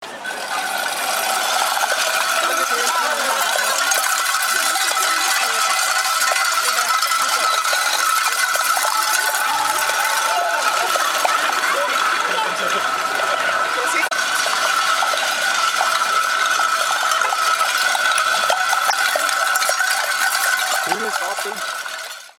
dosenscheppern (mp3, 525 KB)
Auch wenn es so klingt: es sind keine Kuhglocken! :-)
wunderbarer klang!